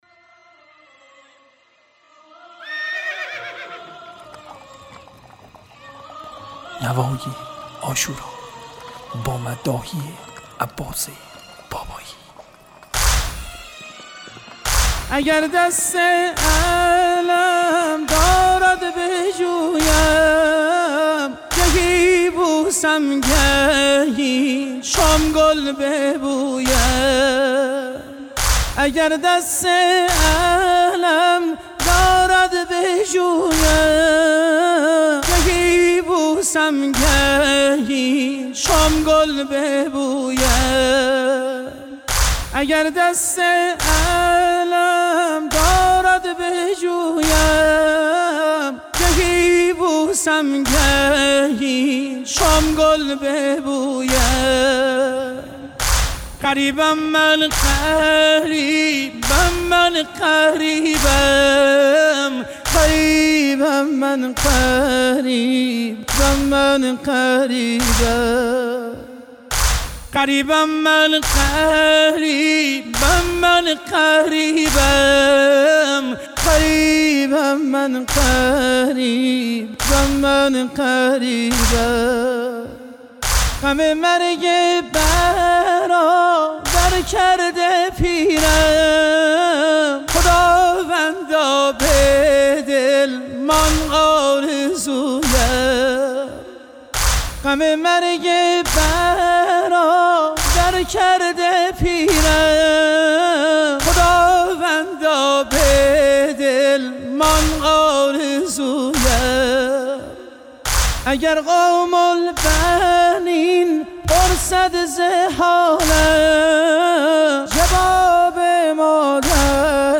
صوت | مداحی شهید بابایی در رثای امام حسین (ع)
به مناسبت عاشورای حسینی، مداحی این شهید بزرگوار را در رثای سید و سالار شهیدان می‌شنوید.
برچسب ها: شهید عباس بابایی ، نوحه قدیمی از عباس بابایی ، شهید بابایی ، محرم